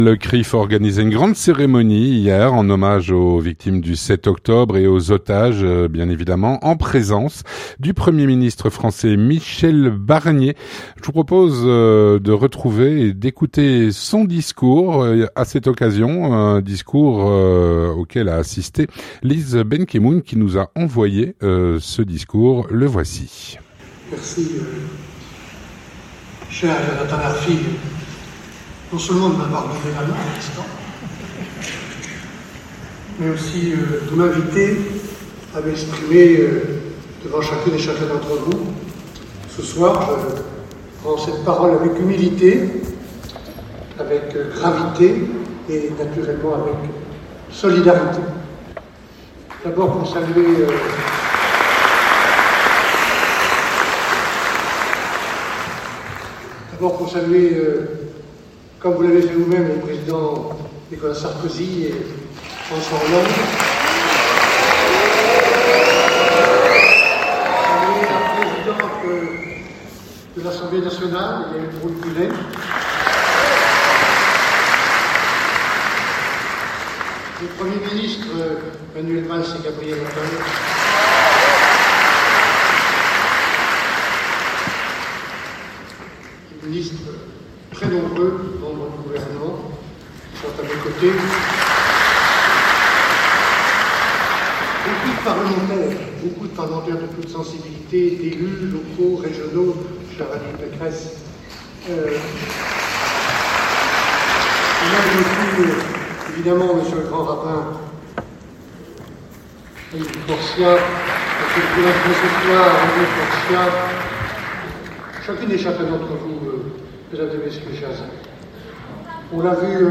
Vue de France - Discours de Michel Barnier lors de l'événement du CRIF d'hier. (08/10/2024)
Le CRIF organisait une grande cérémonie en hommage aux victimes du 7 octobre ainsi qu'aux otages, en présence du premier ministre français, Michel Barnier, dont voici le discours.